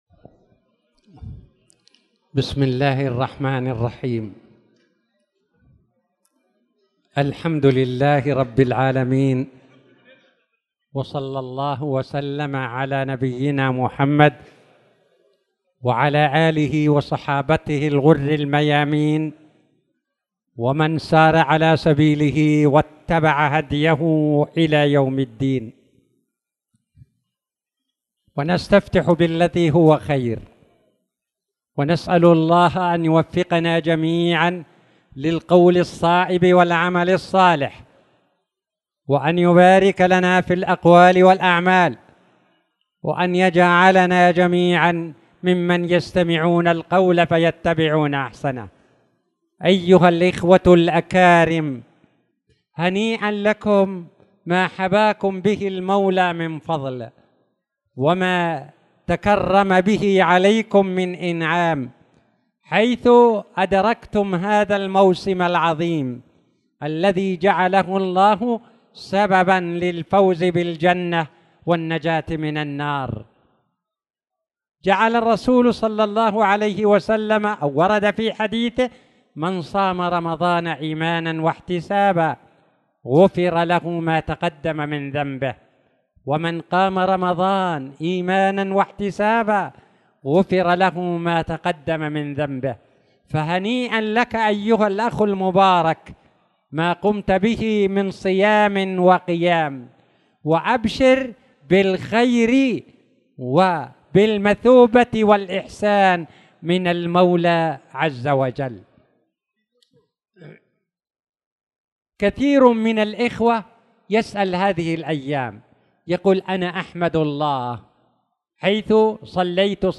تاريخ النشر ٦ شوال ١٤٣٧ هـ المكان: المسجد الحرام الشيخ